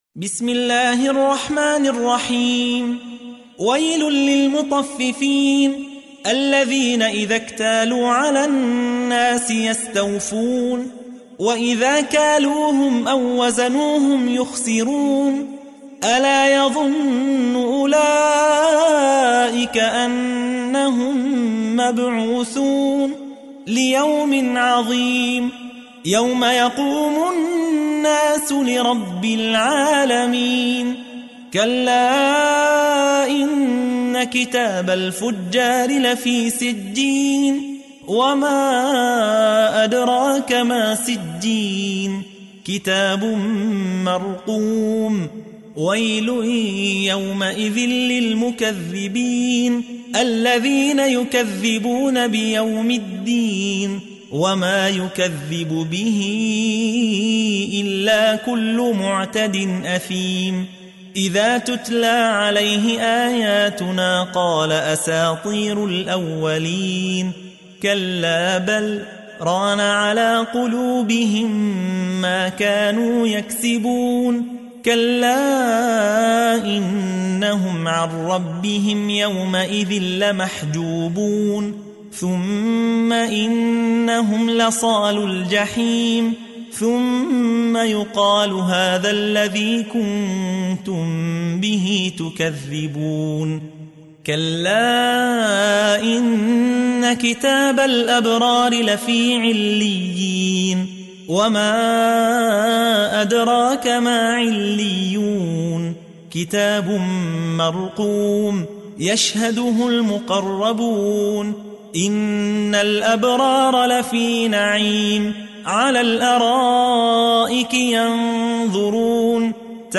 تحميل : 83. سورة المطففين / القارئ يحيى حوا / القرآن الكريم / موقع يا حسين